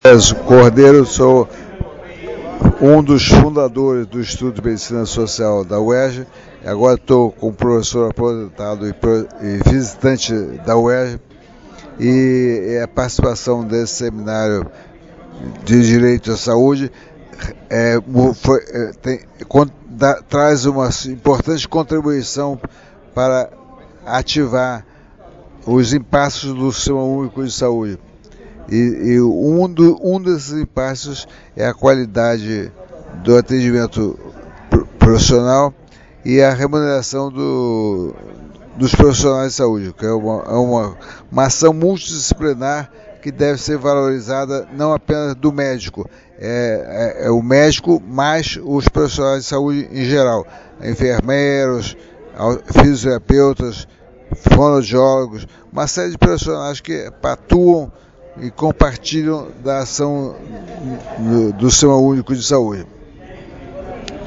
Ouça as opiniões de participantes e palestrantes sobre o Seminário 25 Anos do Direito à Saúde: Integralidade, Responsabilidade e Interdisciplinaridade – Afinal, do que se trata?.